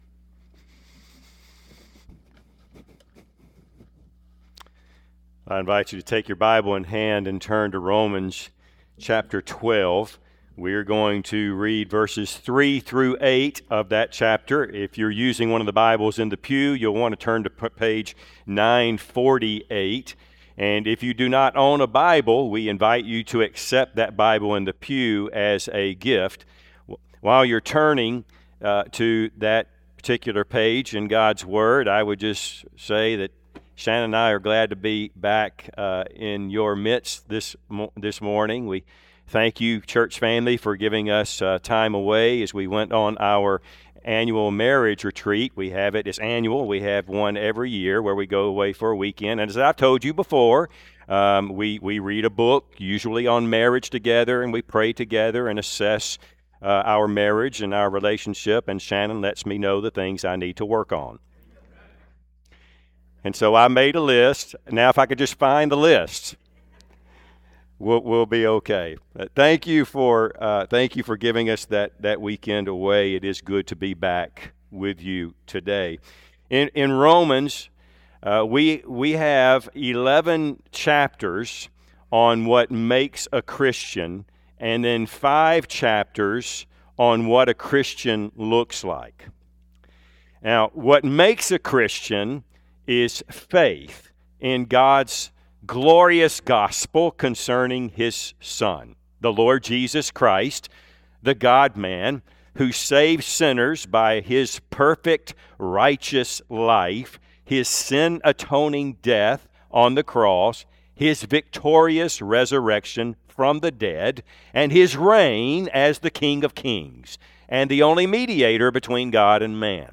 Service Type: Sunday AM Topics: Christian living , Church Membership , Spiritual gifts